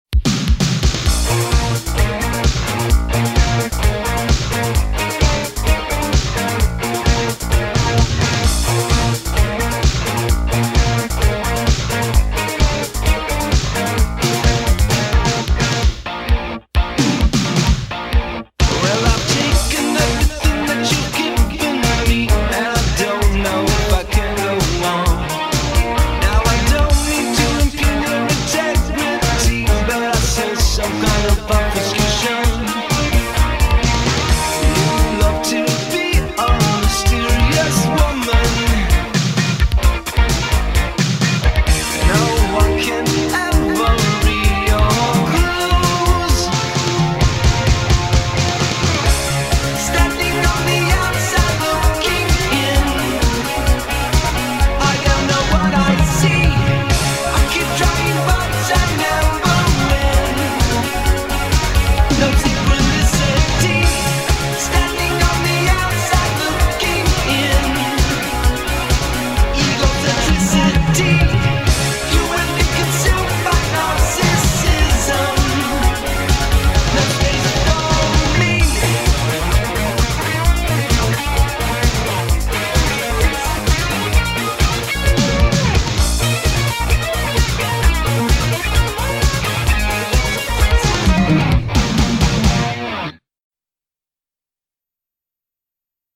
BPM130
I was enamored by the 80’s new wave vibe of this song.